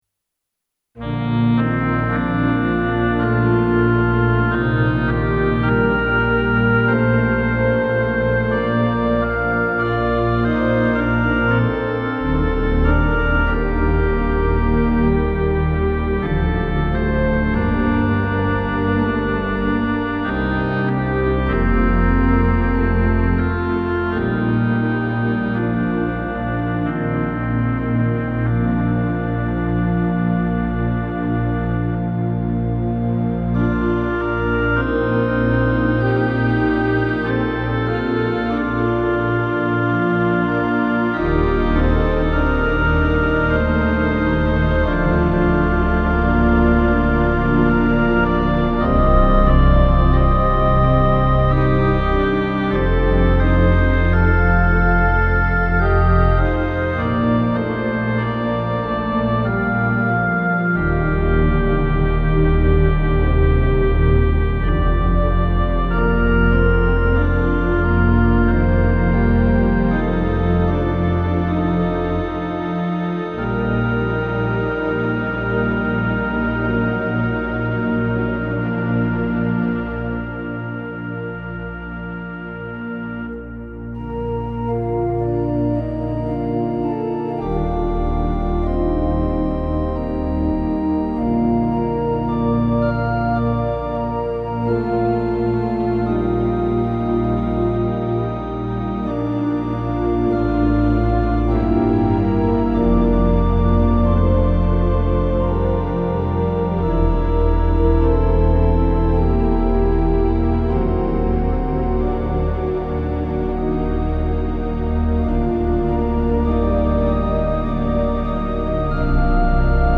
St. James Memorial Episcopal Church
1909 Austin Organ, Opus 246